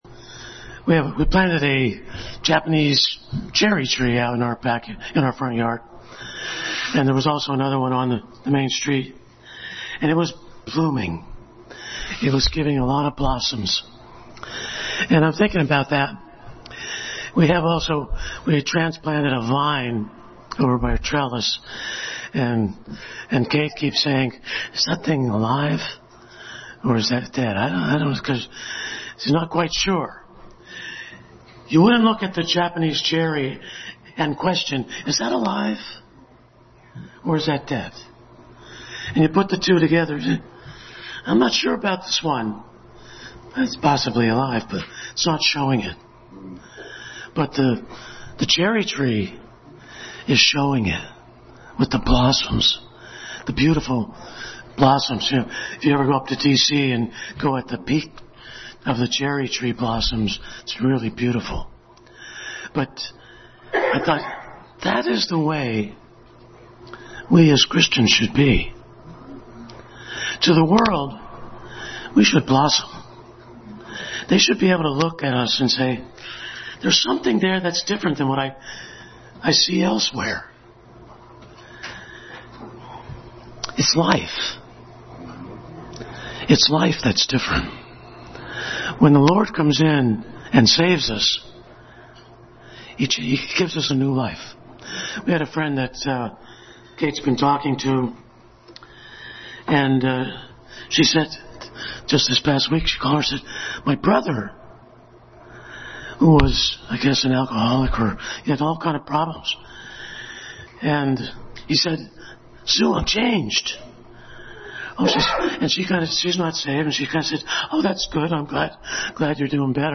The Light of God Passage: Genesis 1:1-4, 2 Corinthians 4:6, Service Type: Family Bible Hour